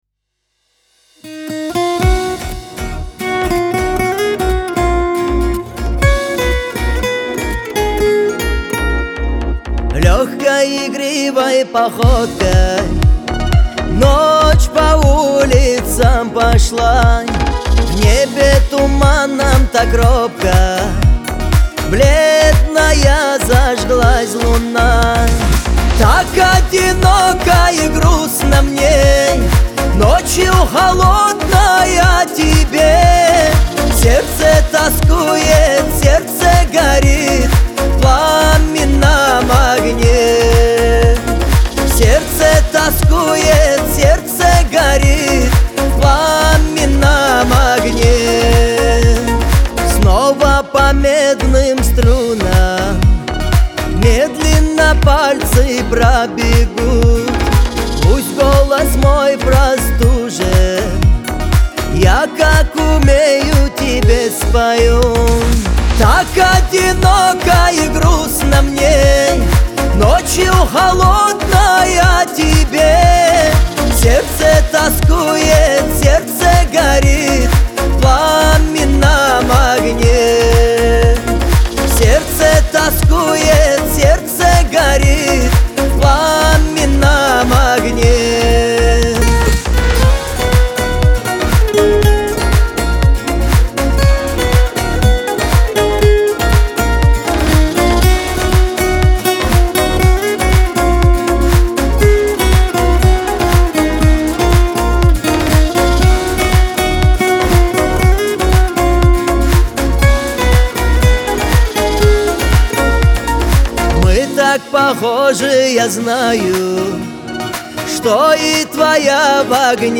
Кавказ – поп
грусть
тоска